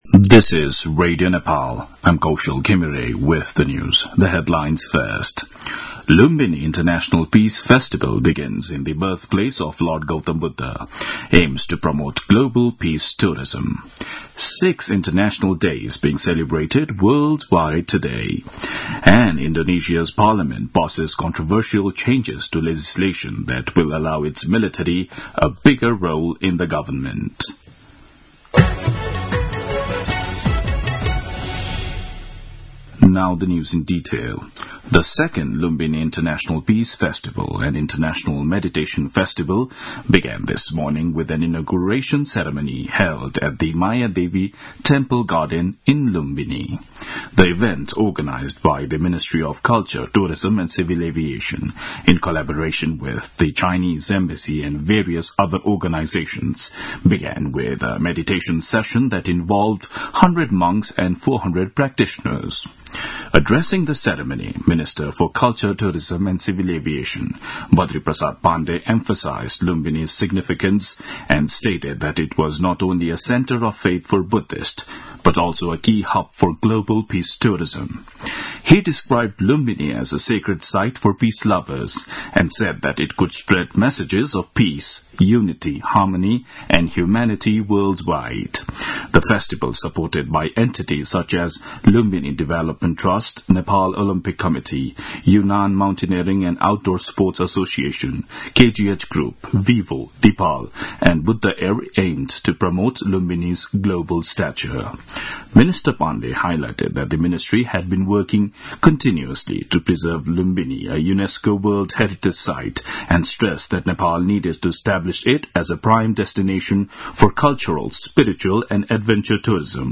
दिउँसो २ बजेको अङ्ग्रेजी समाचार : ८ चैत , २०८१